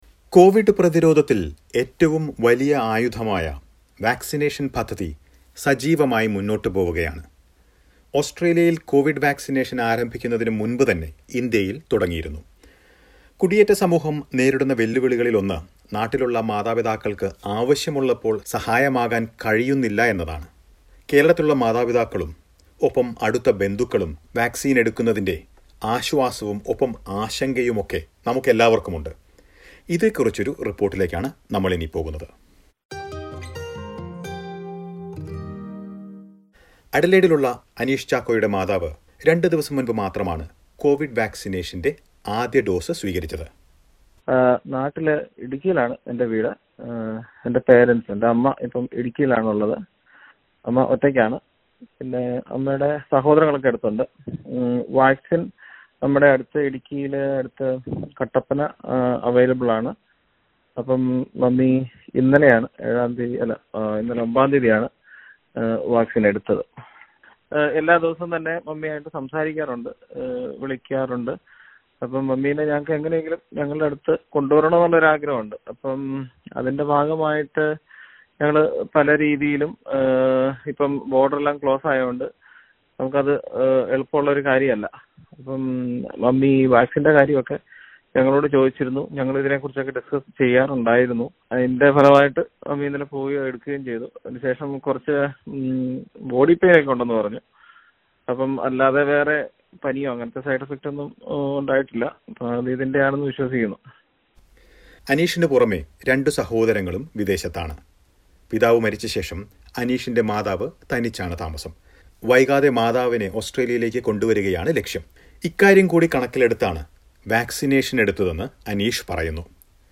കേരളത്തിൽ ഒറ്റയ്ക്ക് കഴിയുന്ന മാതാപിതാക്കൾ വാക്‌സിനേഷൻ എടുക്കുന്നതിന്റെ പ്രതീക്ഷയും ആശങ്കയും ചില ഓസ്‌ട്രേലിയൻ മലയാളികൾ വിവരിക്കുന്നു.